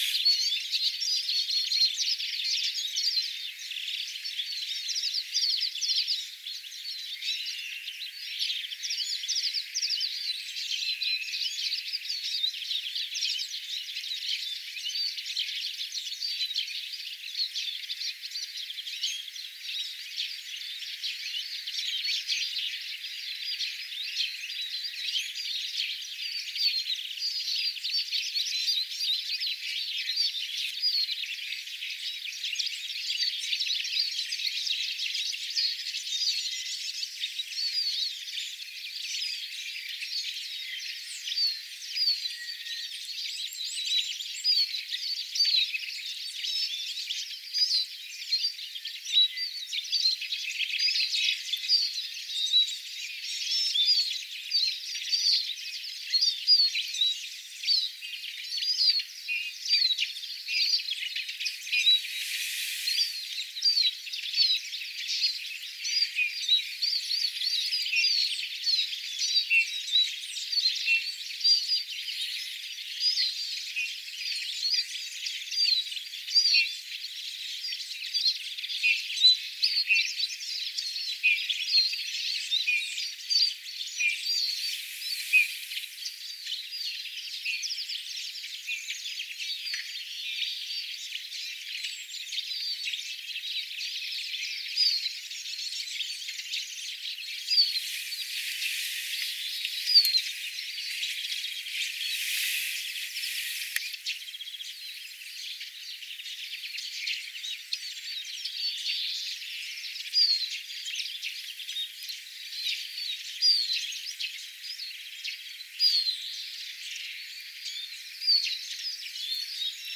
linturuokinnalla linnut ääntelevät
aanimaisema_linturuokinnalla_kevaalla_paljon_lintujen_aantelya.mp3